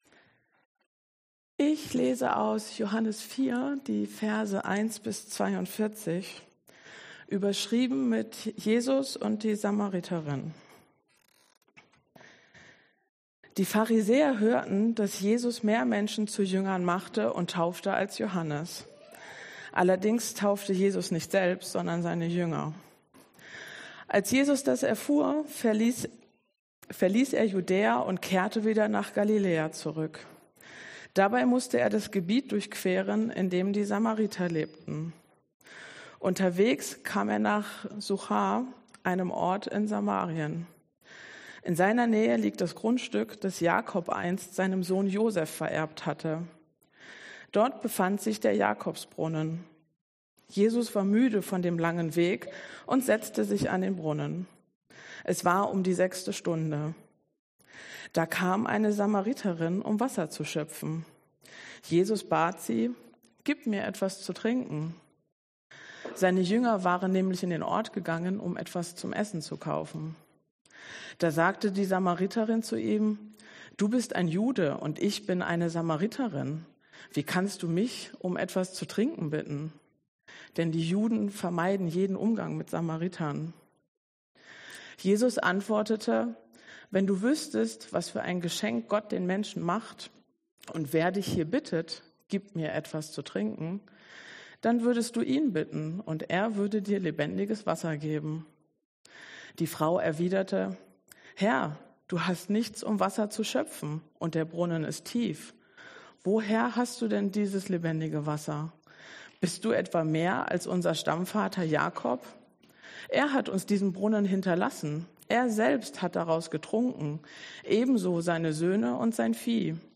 Passage: Johannes 4,1-42 Dienstart: Predigt